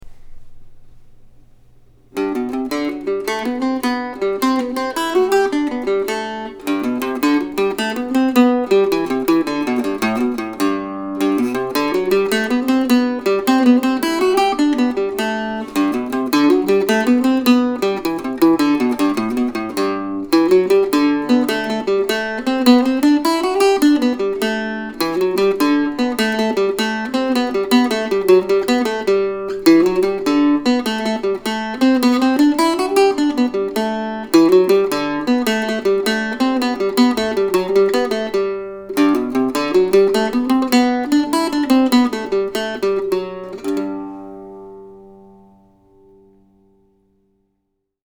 Autumn Suite, November, 2020 (for Octave Mandolin or Mandocello)